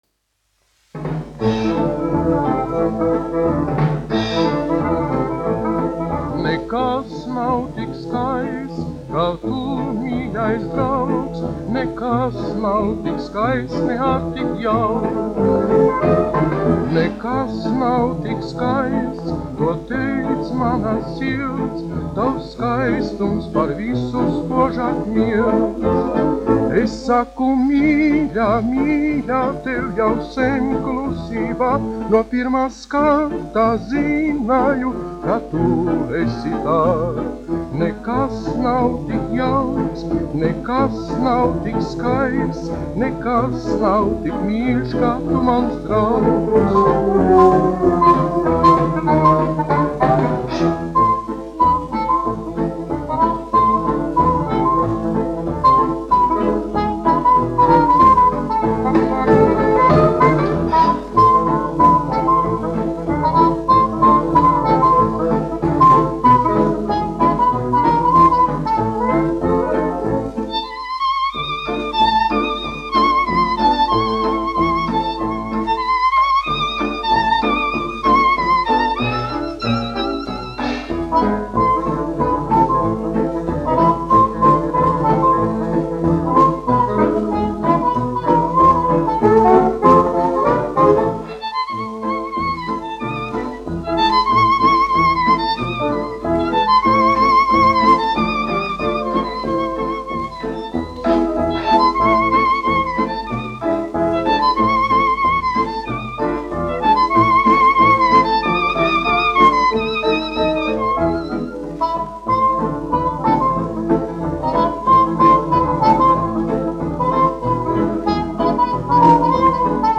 dziedātājs
1 skpl. : analogs, 78 apgr/min, mono ; 25 cm
Fokstroti
Populārā mūzika
Skaņuplate